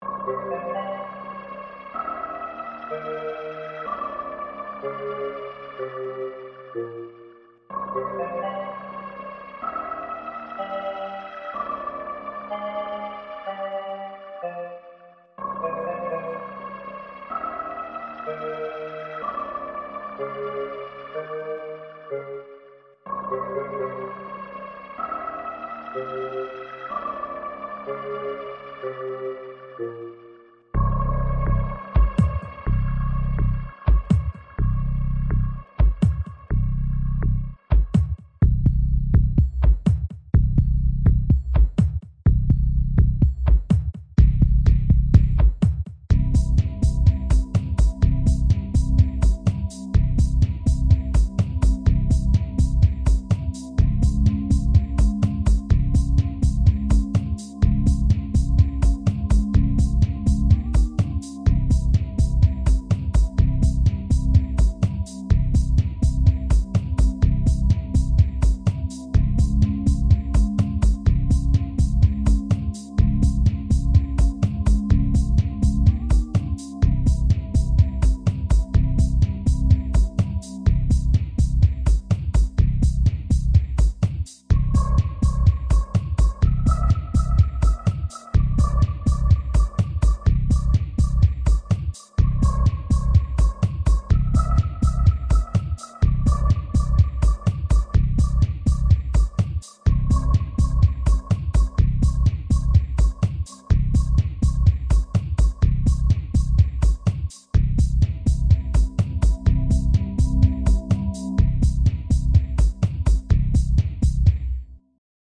• Music is loop-able, but also has an ending